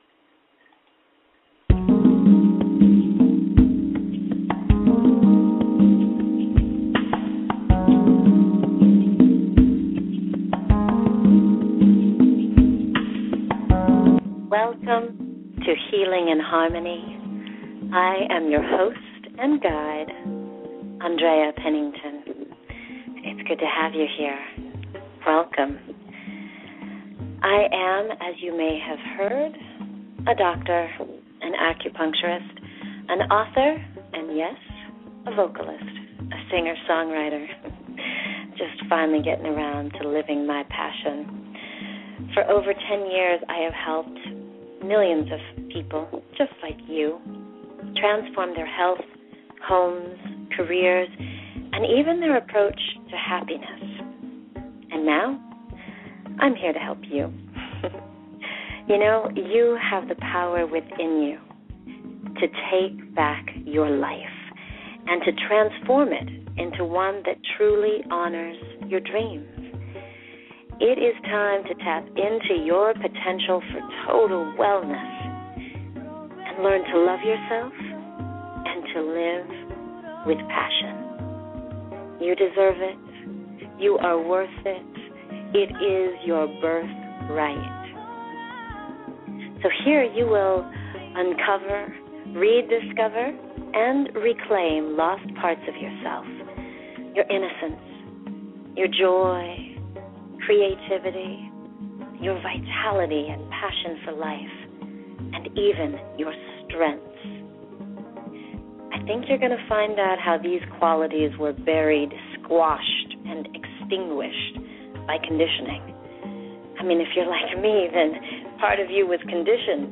This is a good time to reflect on who you REALLY are…not what you may have been programmed to be, but who you are naturally, effortlessly. Listen in to remember your own Truth and enjoy the meditation and music.